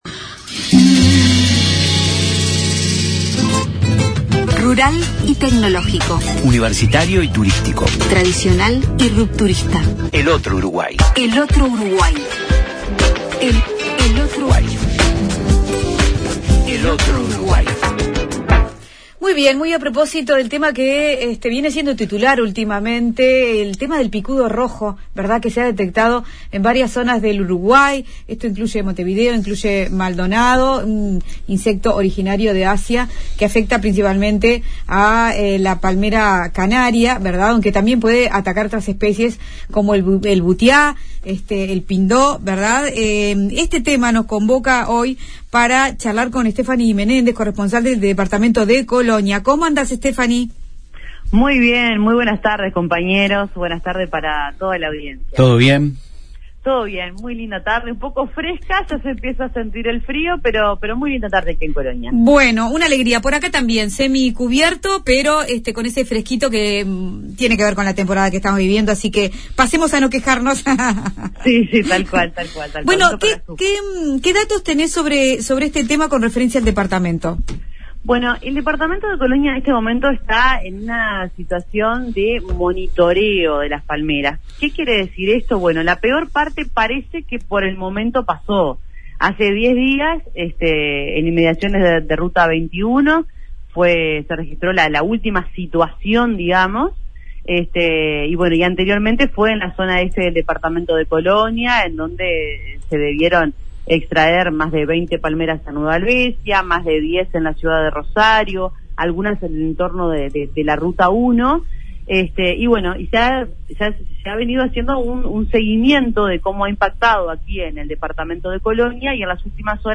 Reorrida por las palmera de la entrada a Colonia del Sacramento
Informe